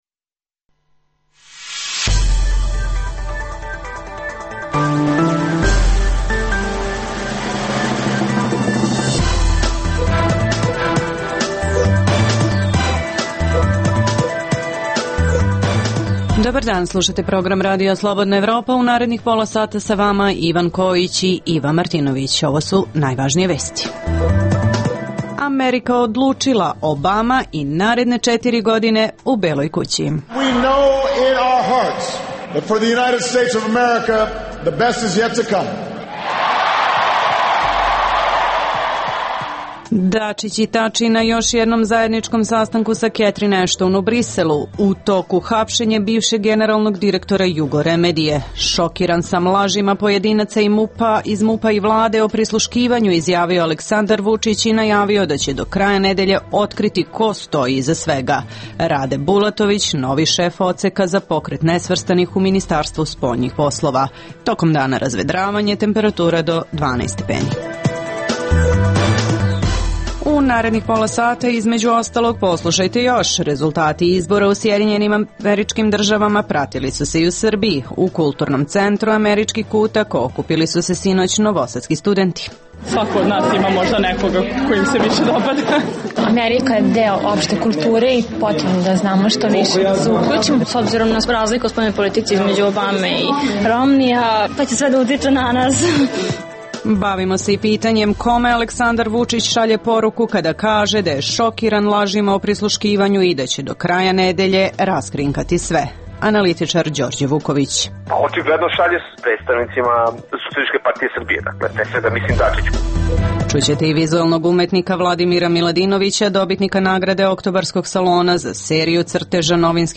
U emisiji poslušajte: - Amerika odlučila – Obama u Beloj kući i naredne četiri godine. Poslušajte pobednički govor reizabranog predsednika, čestitku koju mu je uputio protivkandidat Mit Romni, te atmosferu sa njujorškog Times Squera.